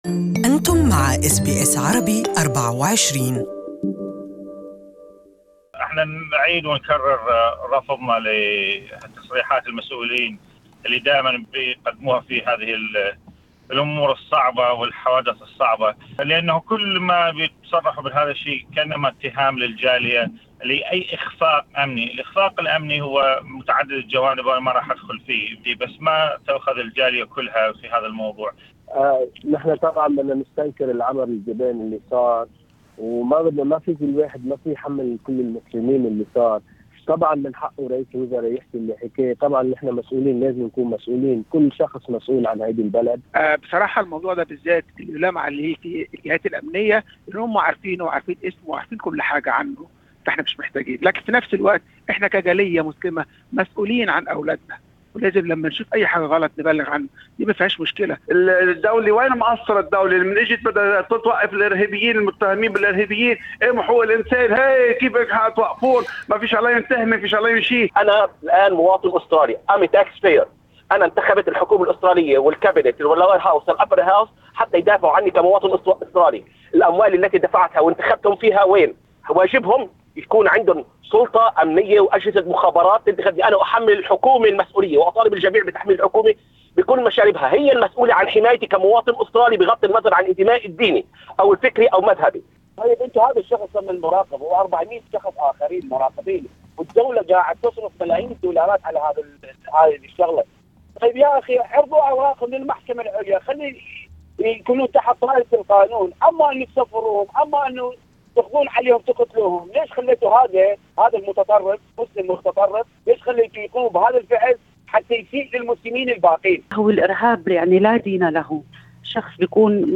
Arab Australians commenting share their thoughts on the Melbourne attack